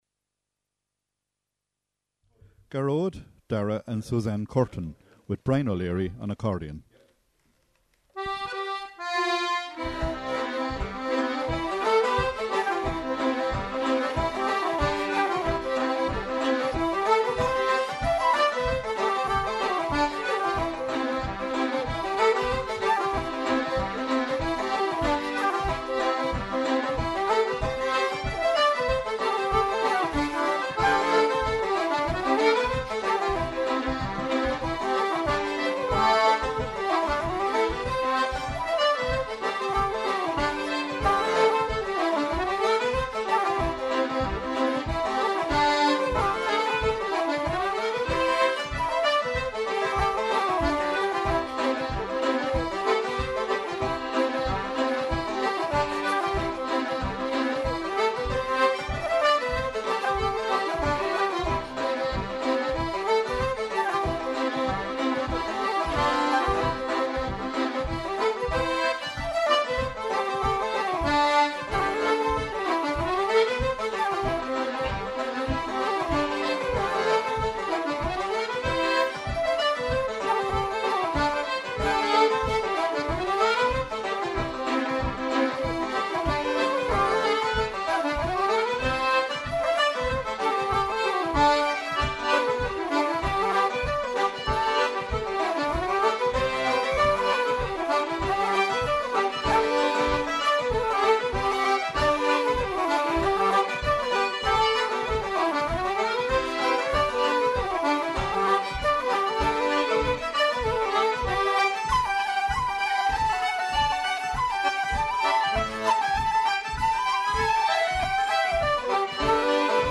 Highlights from Fleadh Cheoil na hÉireann 2011 in Cavan.